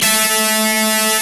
Index of /90_sSampleCDs/Roland L-CDX-01/GTR_Distorted 1/GTR_Power Chords